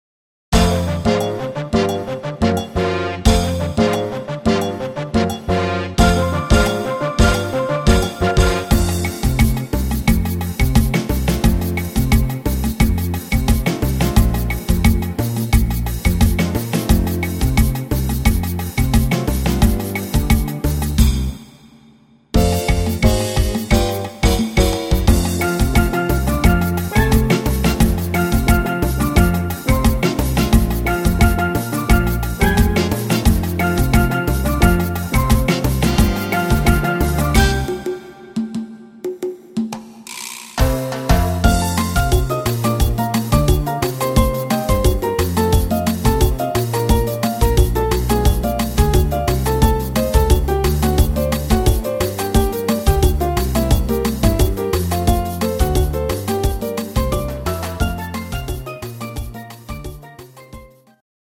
Bar Piano